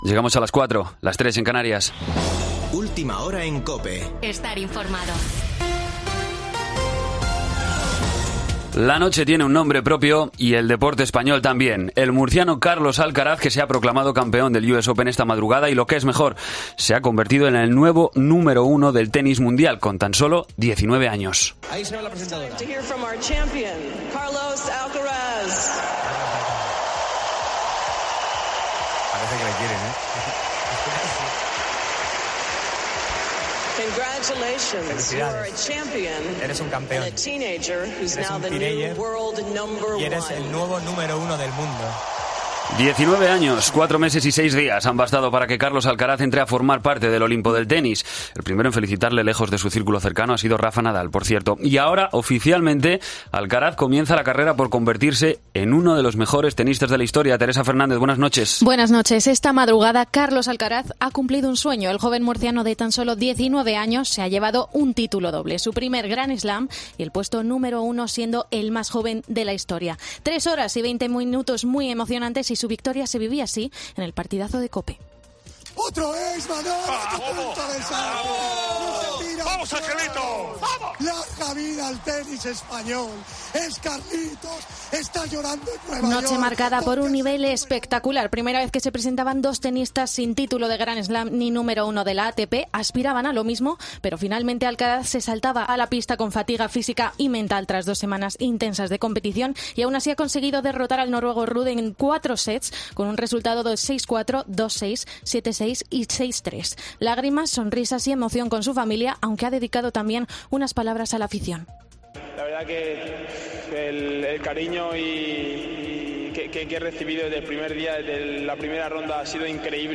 Boletín de noticias COPE del 12 de septiembre a las 04:00 horas
AUDIO: Actualización de noticias Herrera en COPE